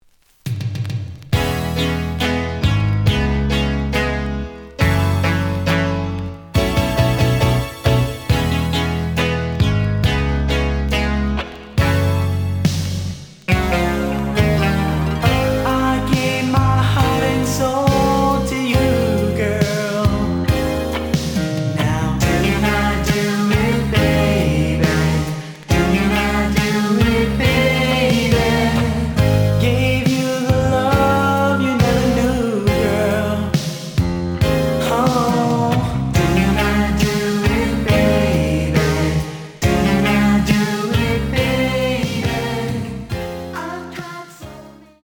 The audio sample is recorded from the actual item.
●Genre: Hip Hop / R&B
Slight damage on both side labels. Plays good.)